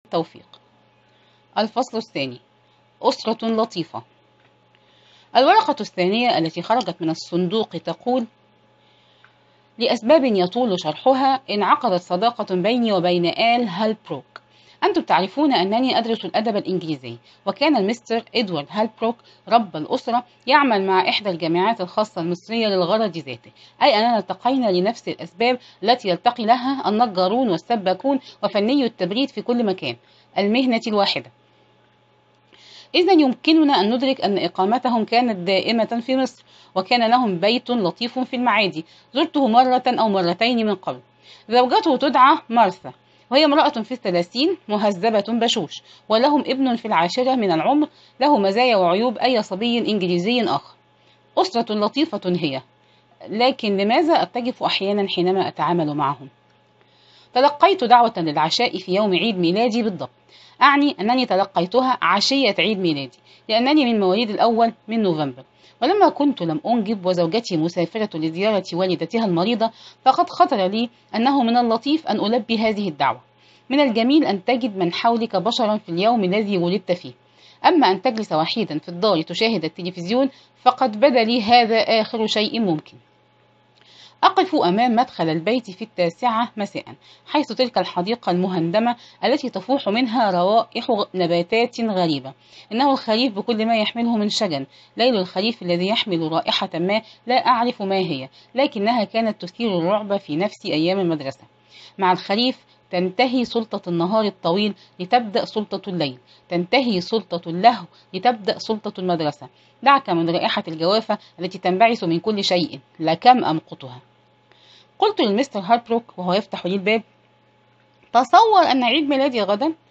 أرشيف الإسلام - الكتب مسموعة - كتب قصص وروايات - الآن نفتح الصندوق - أحمد خالد توفيق مجموعة قصصية